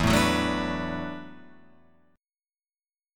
E7sus2sus4 chord